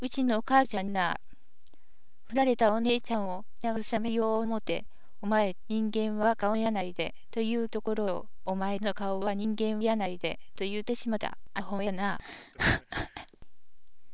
Chatr: a talking machine
different styles of talking